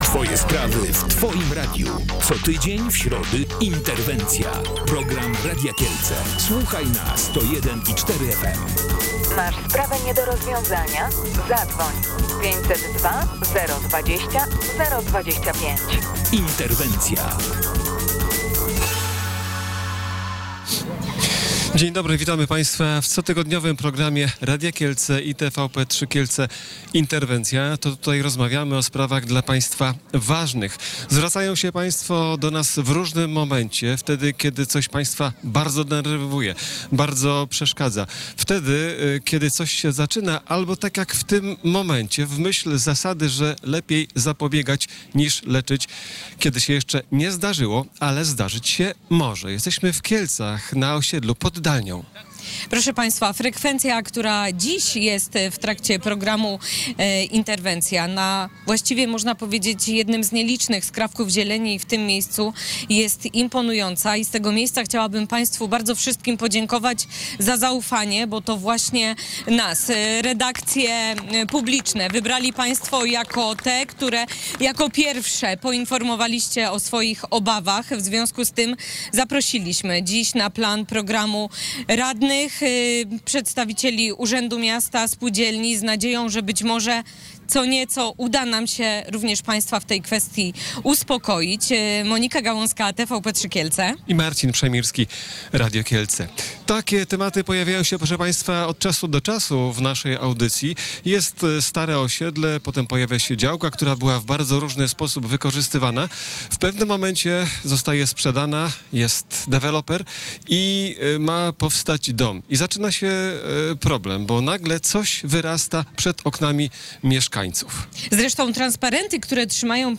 Mieszkańcy ulicy Naruszewicza na kieleckim osiedlu Pod Dalnią obawiają się, że deweloper wybuduje tam ponad dwudziestopiętrowy budynek. To oznacza mniej zieleni i kłopoty z parkowaniem. O tym rozmawialiśmy w programie Interwencja.